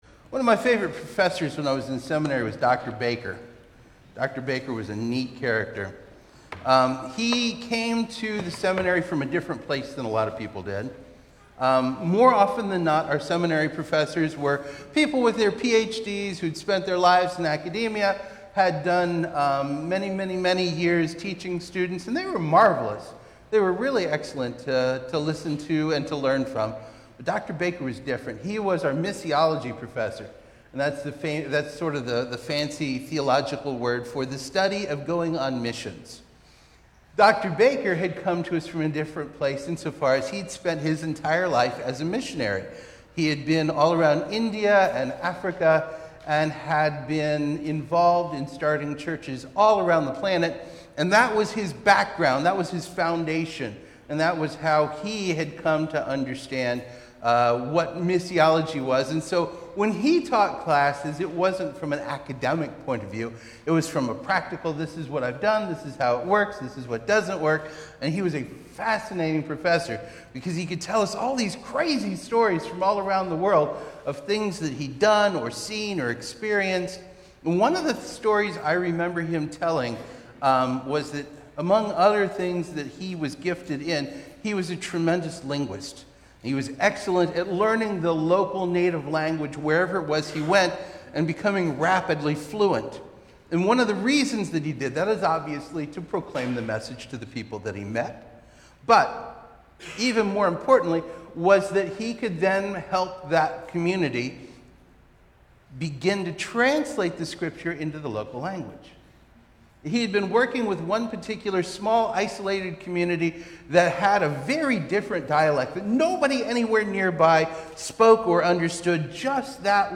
Easter-Sermon-042025.mp3